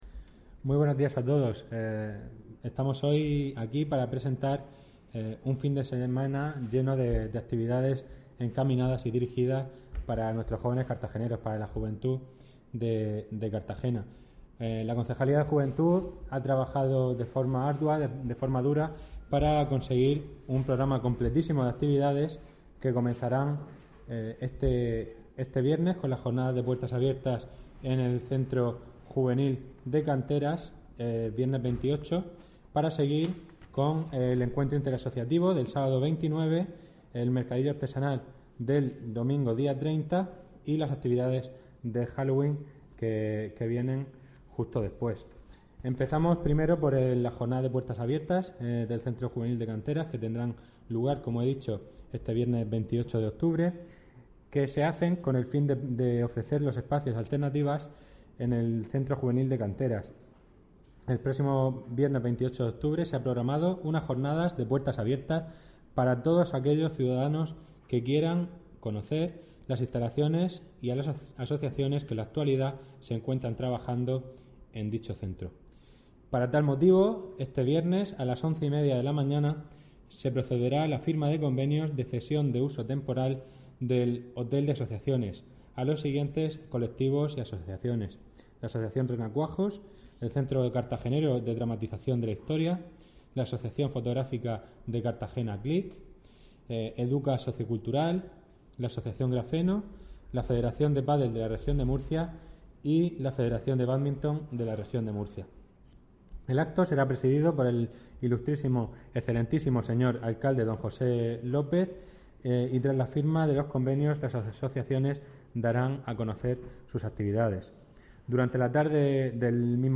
Audio: 2016-10-26 Rueda de prensa actividades de Juventud (MP3 - 4,66 MB)